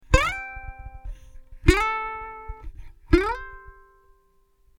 Slide in from a mute low on the neck, pick, and squeeze on the way so that you end in a note.
slide-in.mp3